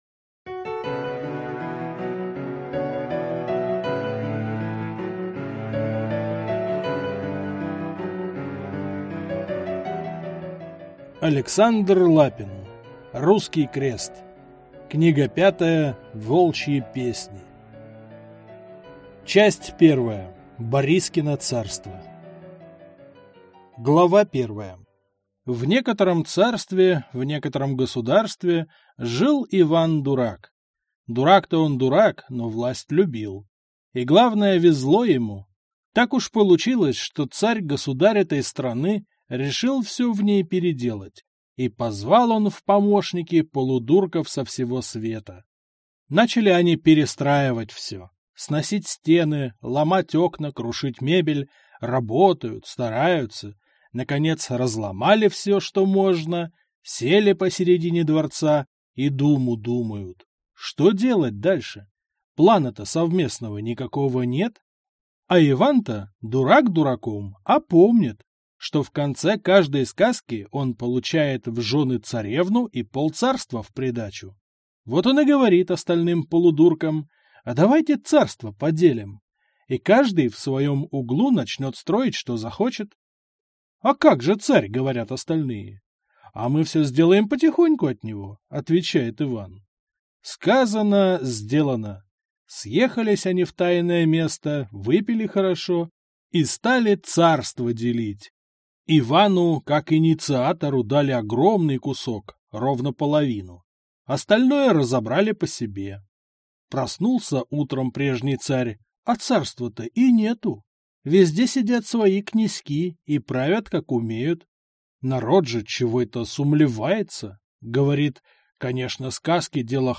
Аудиокнига Волчьи песни | Библиотека аудиокниг